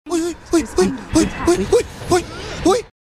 • Качество: 129, Stereo
веселые
голосовые